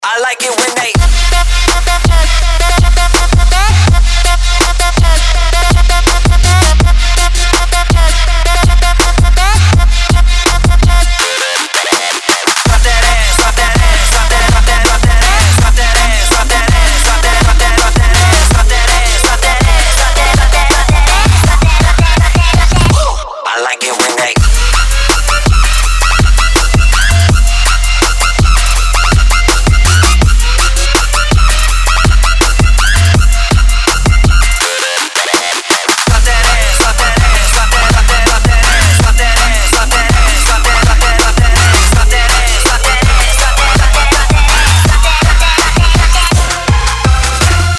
• Качество: 320, Stereo
Trap
CrunkStep